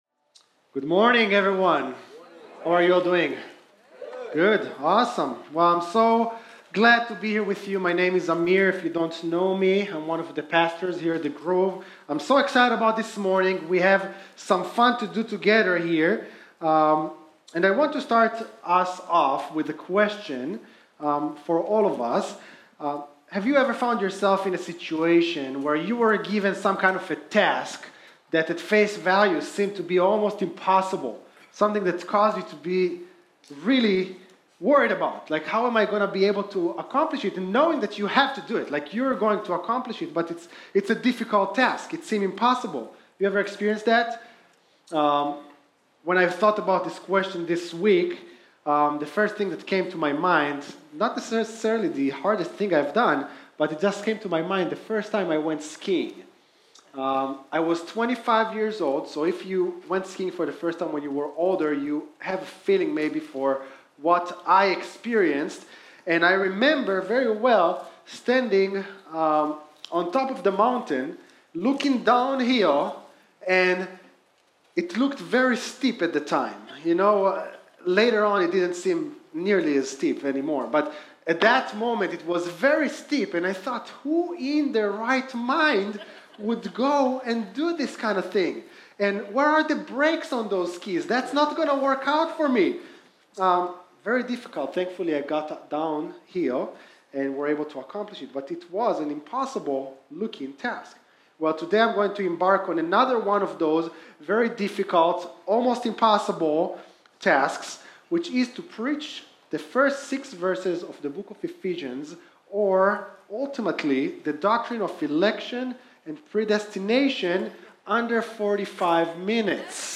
Home Sermons Transformed